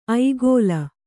♪ aigōla